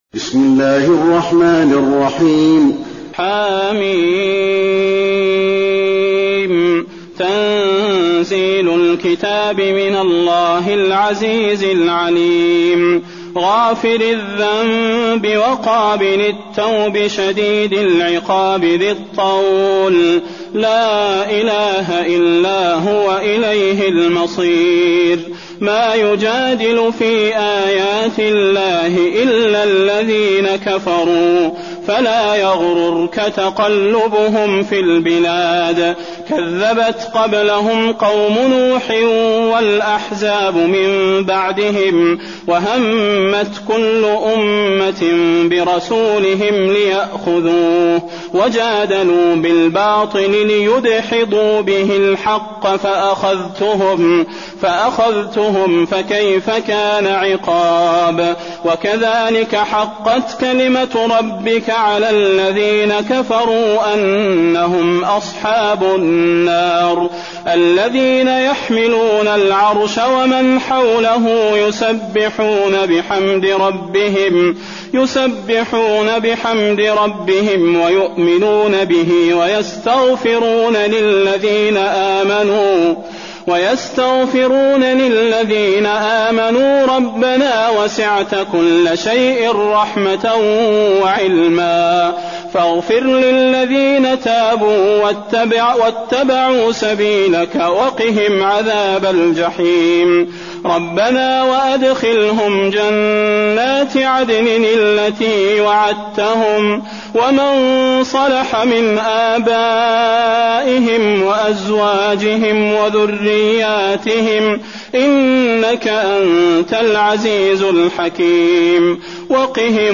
المكان: المسجد النبوي غافر